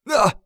XS受伤02.wav
XS受伤02.wav 0:00.00 0:00.47 XS受伤02.wav WAV · 41 KB · 單聲道 (1ch) 下载文件 本站所有音效均采用 CC0 授权 ，可免费用于商业与个人项目，无需署名。